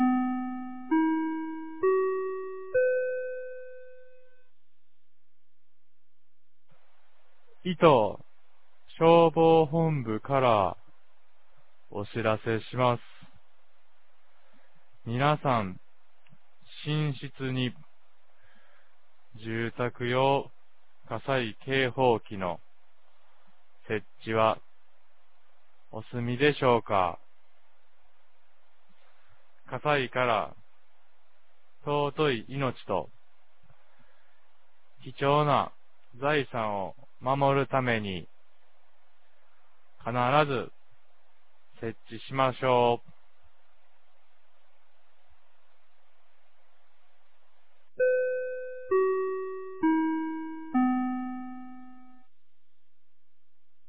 2025年09月29日 10時01分に、九度山町より全地区へ放送がありました。